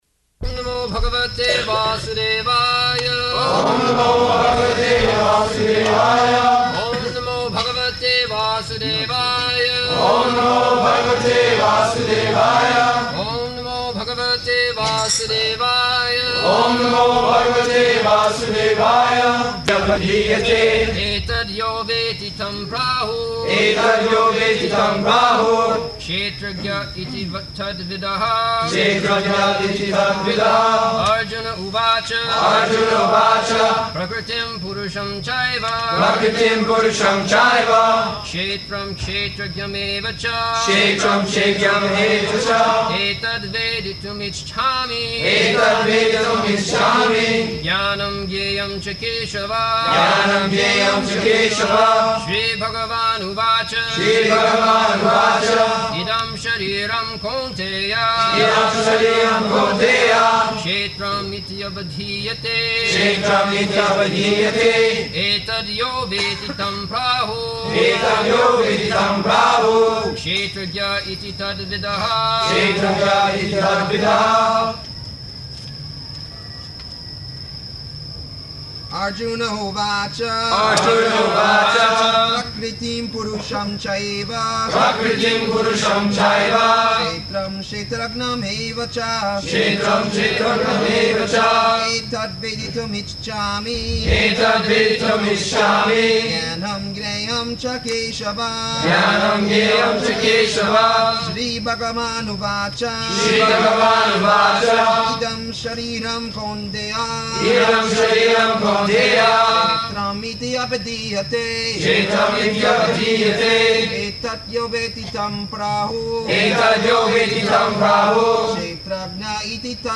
February 25th 1975 Location: Miami Audio file
[devotees repeat] [leads chanting of verses, etc.]